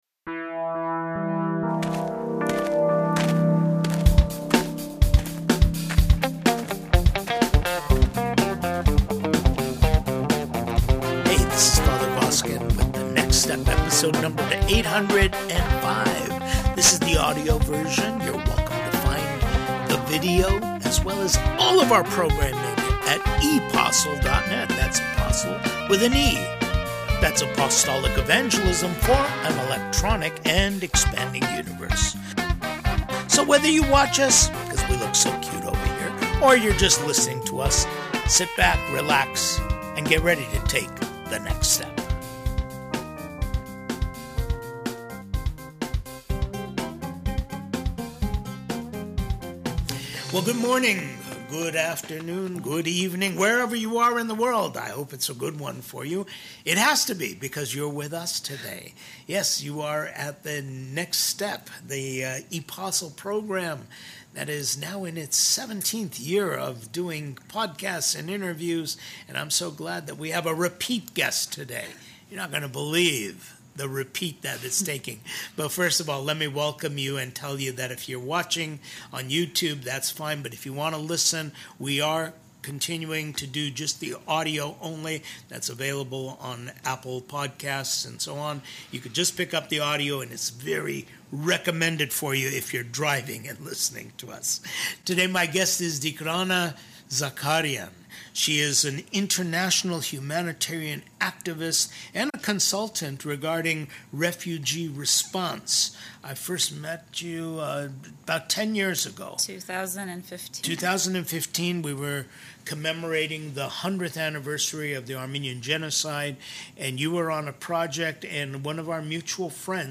Candid Conversation
Refugee Response Coordinator This is the soundtrack (AUDIO ONLY) of Next Step #805 Watch the Video at